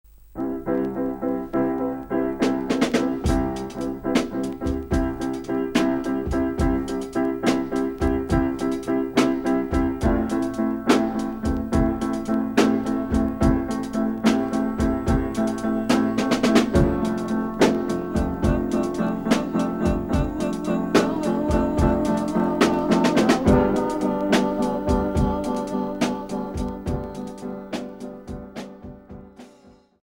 Expérimental